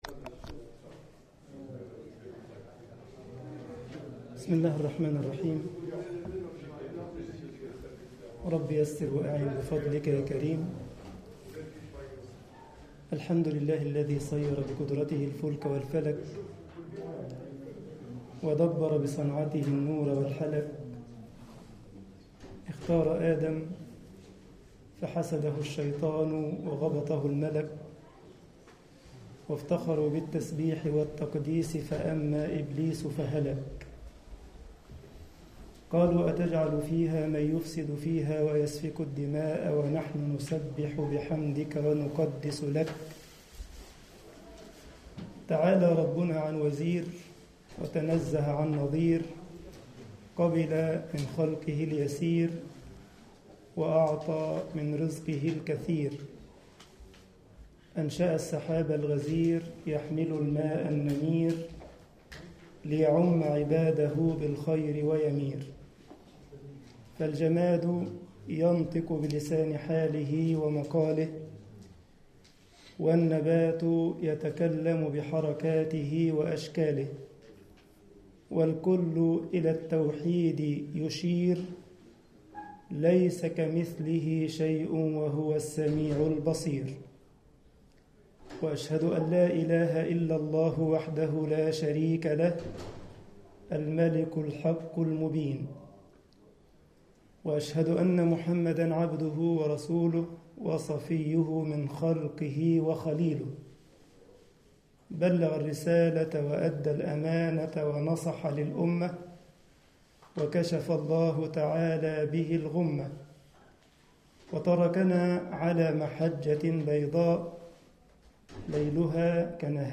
مدينة كايسرسلاوترن - ألمانيا